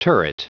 Prononciation du mot turret en anglais (fichier audio)